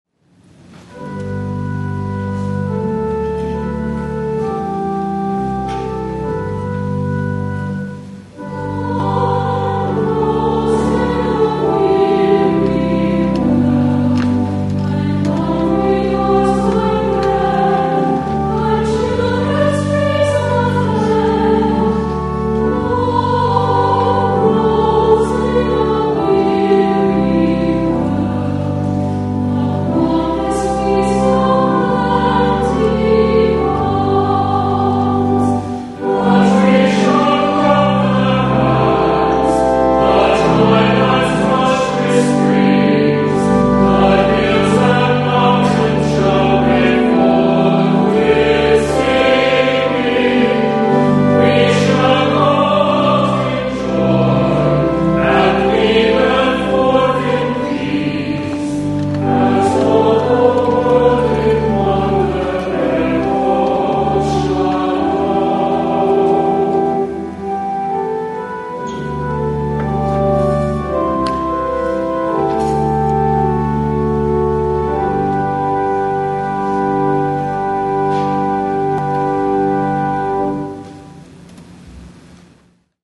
Morningside Presbyterian Church, Atlanta
* THE CHORAL RESPONSE